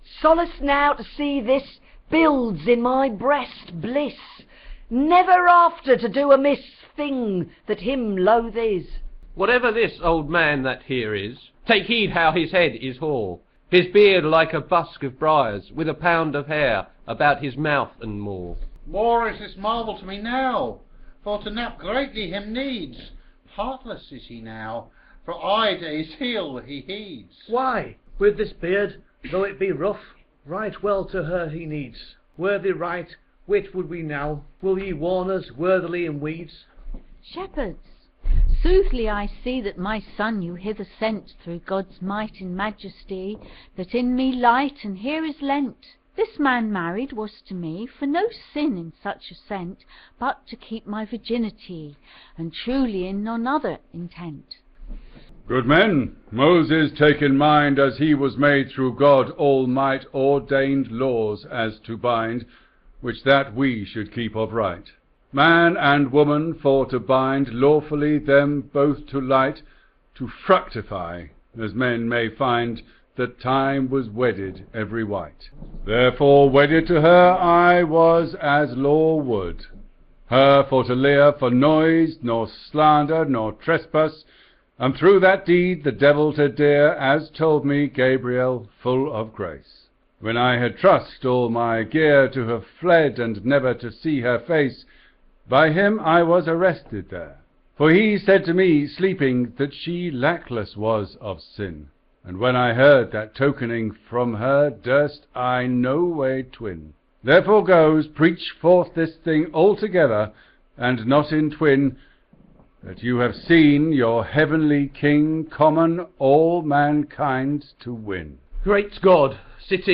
Exploring the Chester Mystery Plays is a series of live streamed events where the Chester plays are performed with readers and commentary. Rough round the edges, edited versions of these events are now being posted online. This post completes play 7, The Shepherds, where our four sheep herds (and various boys) pay homage to Jesus.